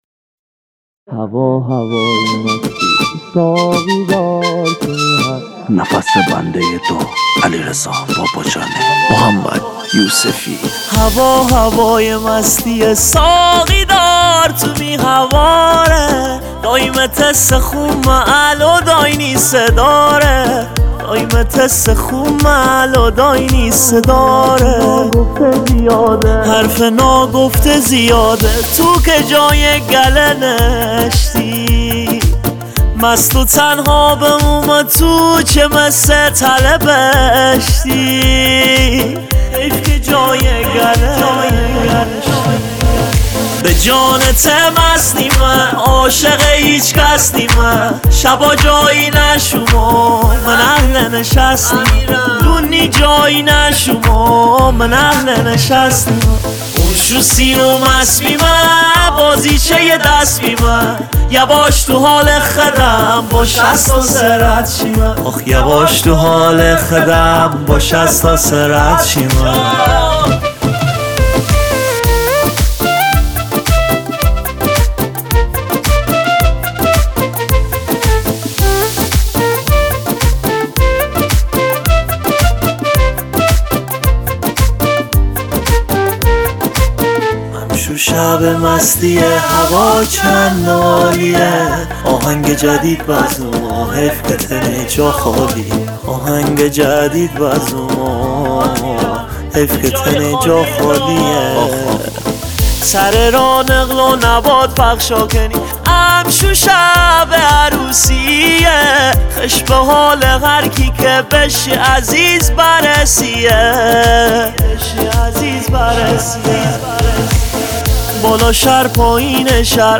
آهنگ جدید فارسی و محلی